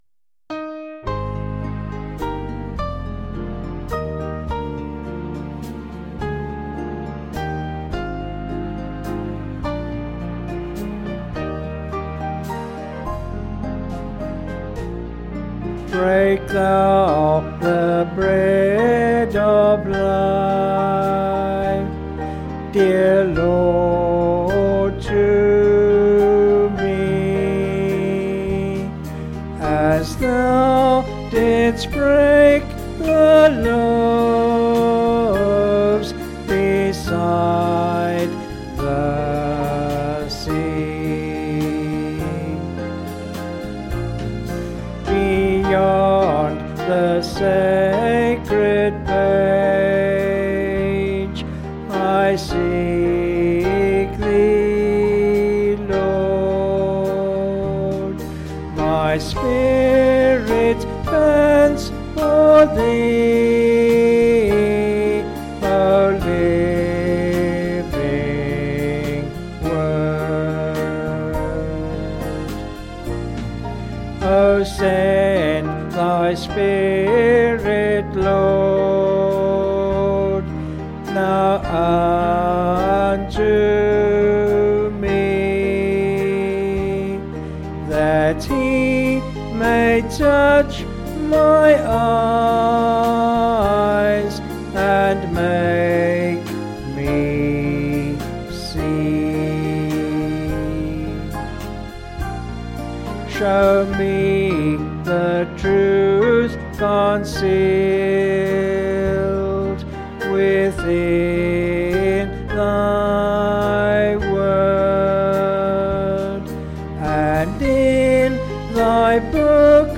Vocals and Band   264.5kb Sung Lyrics